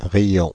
Ääntäminen
Ääntäminen Paris: IPA: [ʁi.jɔ̃] France (Île-de-France): IPA: /ʁi.jɔ̃/ Haettu sana löytyi näillä lähdekielillä: ranska Käännöksiä ei löytynyt valitulle kohdekielelle. Riions on sanan rire taipunut muoto.